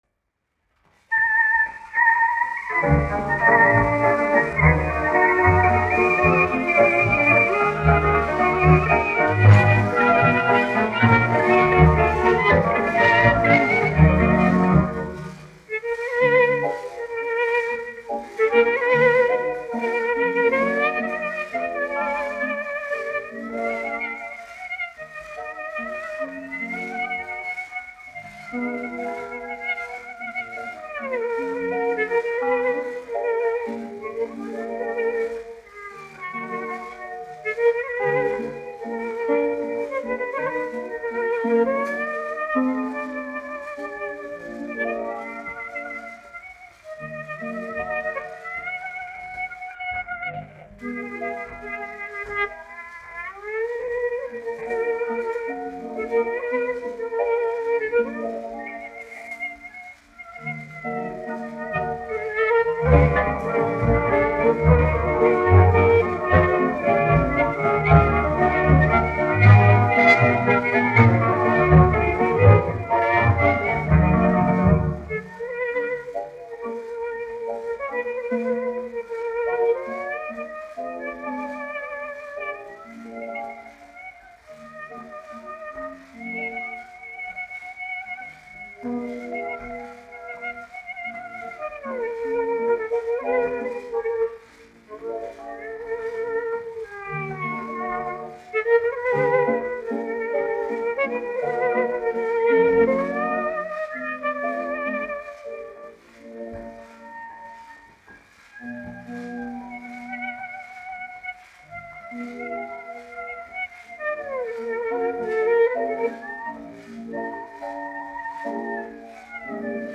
1 skpl. : analogs, 78 apgr/min, mono ; 25 cm
Populārā instrumentālā mūzika
Romances (mūzika)
Latvijas vēsturiskie šellaka skaņuplašu ieraksti (Kolekcija)